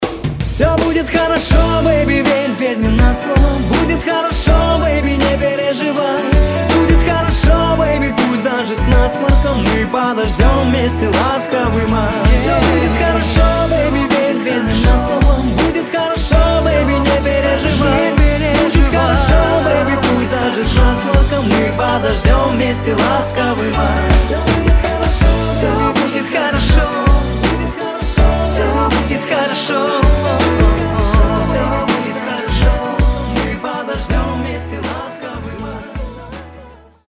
• Качество: 192, Stereo
позитивные